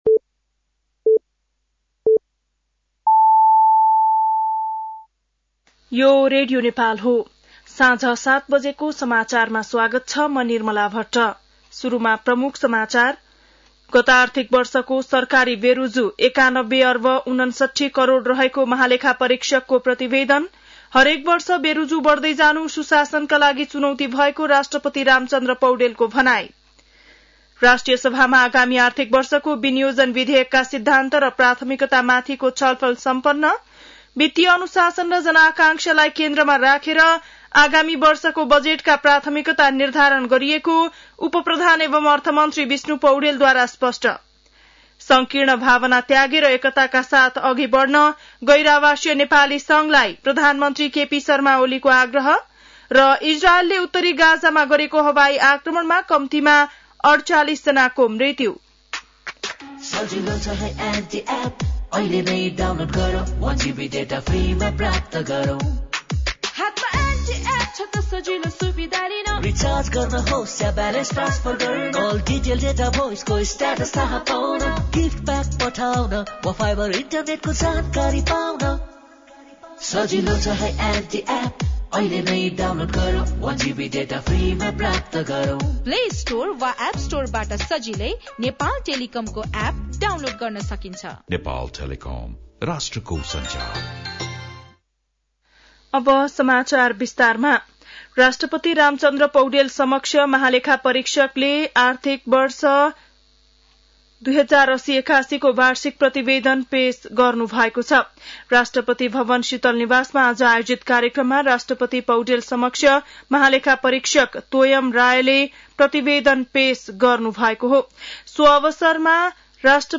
बेलुकी ७ बजेको नेपाली समाचार : ३१ वैशाख , २०८२